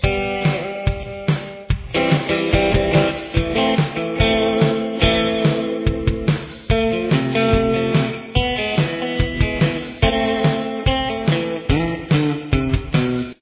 Tempo: 143 beats per minute
Key Signature: A minor
Lead Guitar